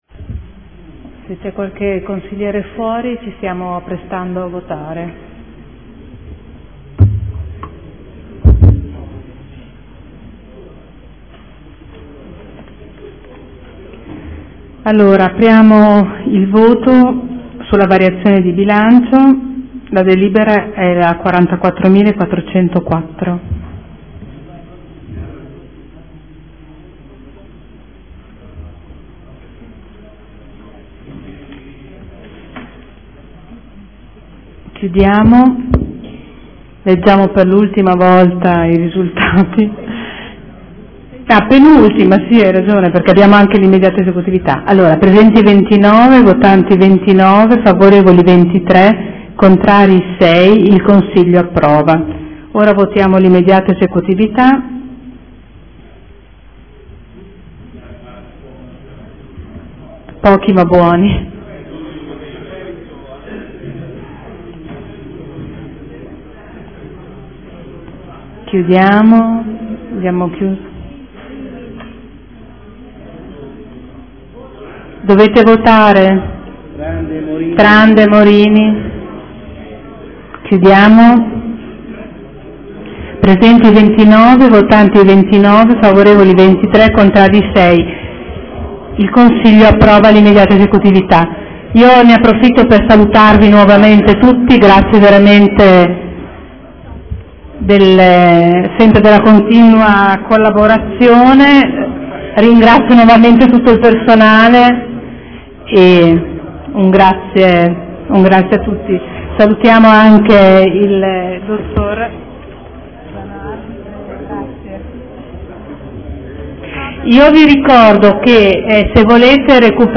Seduta del 28/04/2014 Mette ai voti. Bilancio di Previsione Armonizzato 2014/2016 – Piano Investimenti 2014/2016 – Programma Triennale dei Lavori Pubblici 2014/2016 – Documento Unico di Programmazione 2014/2016 – Variazione di Bilancio n. 1 – Riadozione e conferma degli schemi di Bilancio.